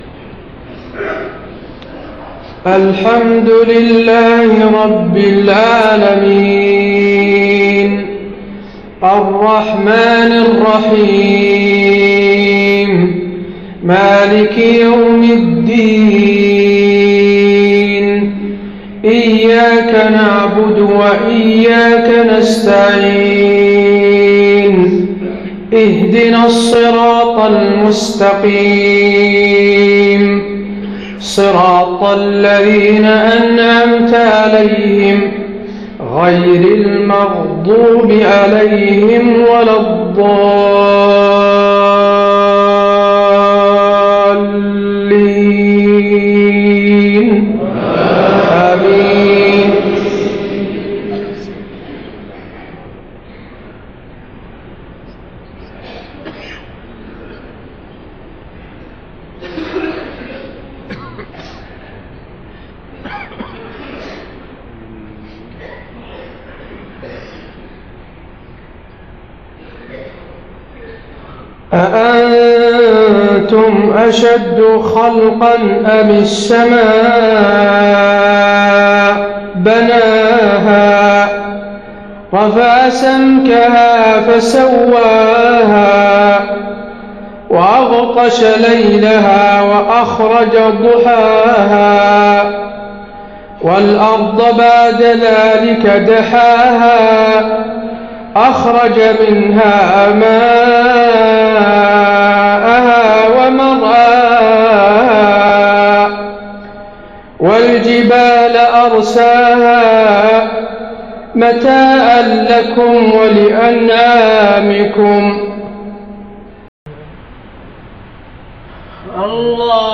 صلاة المغرب 16 محرم 1430هـ من سورة النازعات 27-41 > 1430 🕌 > الفروض - تلاوات الحرمين